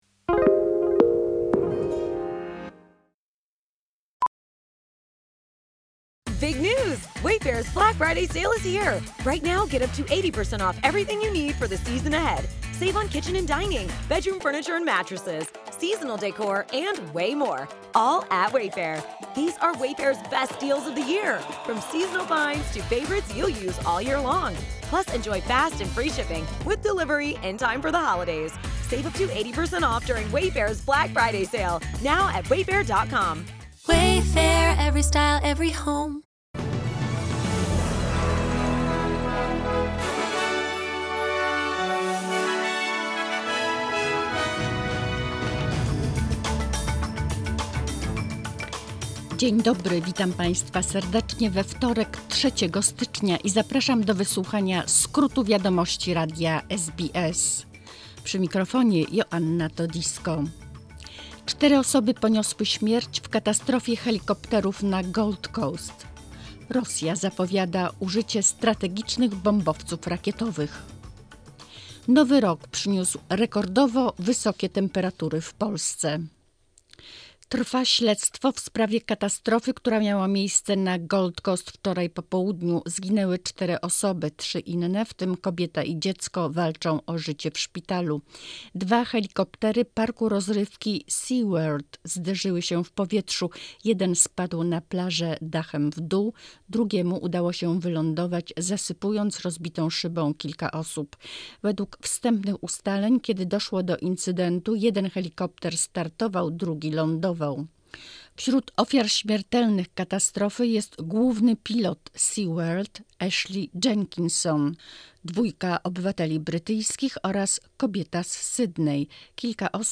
Wiadomości 3 stycznia 2023 - SBS News Flash